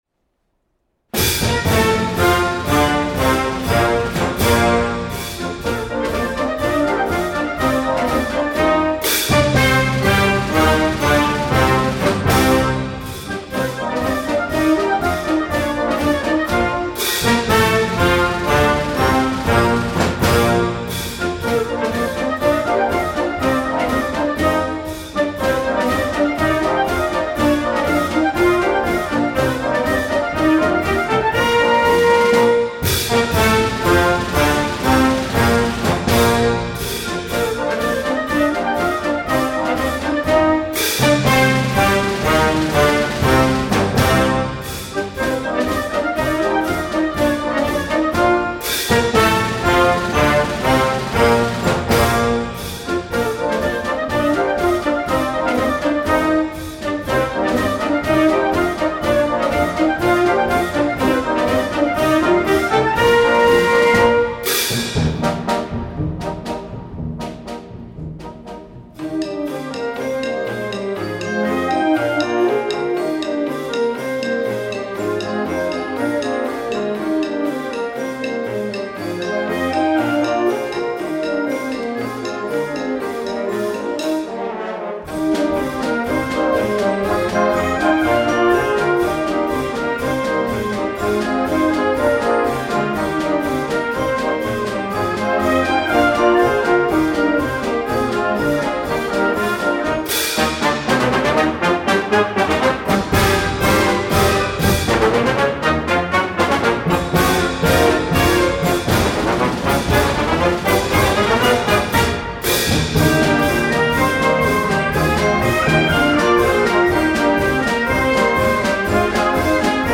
she had a special military march at the ready